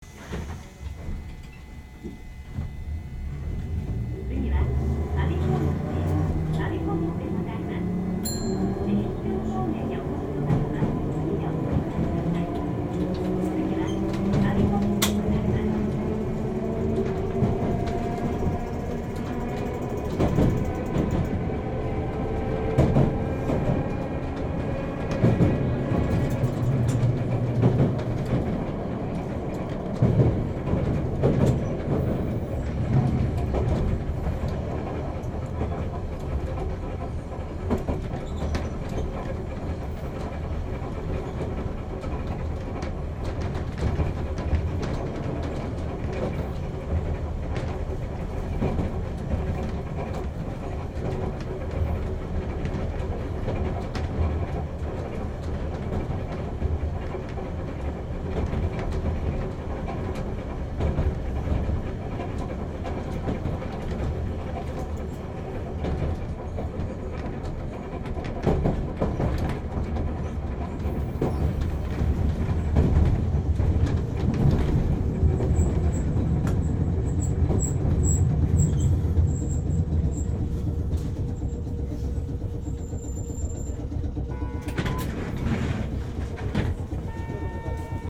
目的は車内走行音の録音です。
写真と音声は関係ありません 上は大和川駅を出たモ１６１形が大和川橋梁を渡り、我孫子道に到着するまでの走行音です。